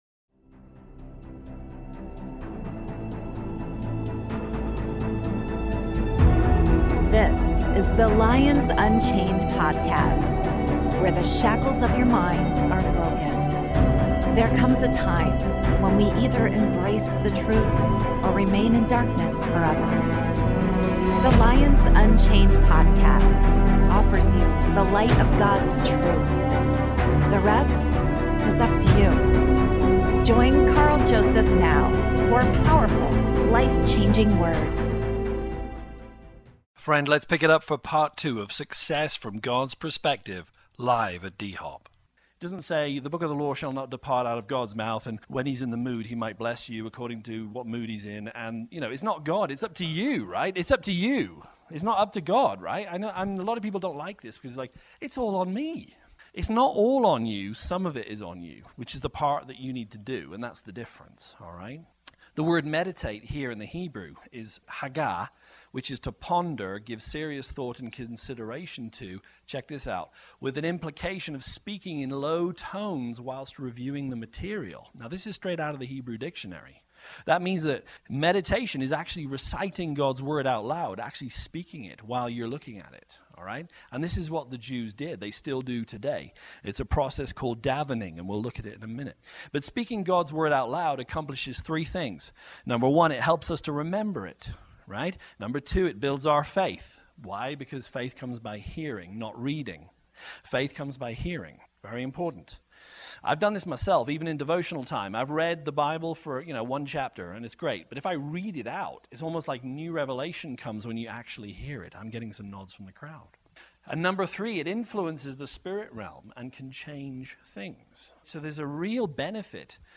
Success from God's perspective: Part 2 (LIVE)-Christians can learn a lot from biblical meditation.